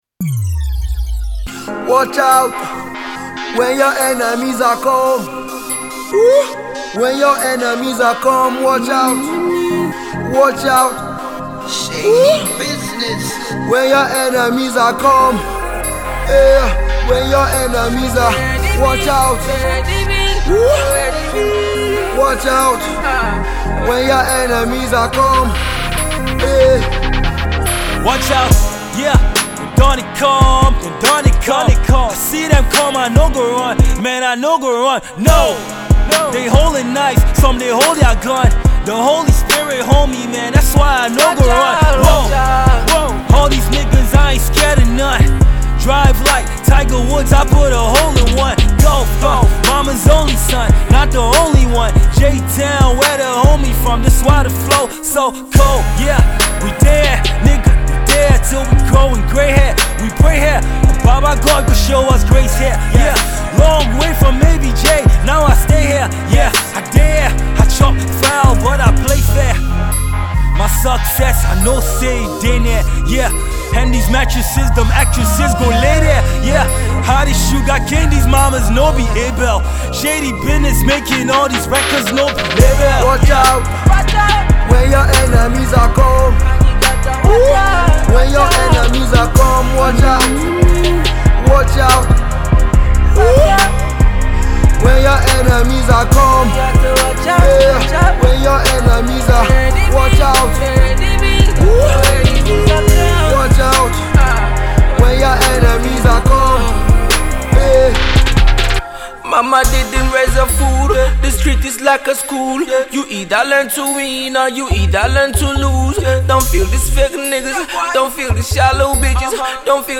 Eclectic Producer
Afro-soul singer
emcee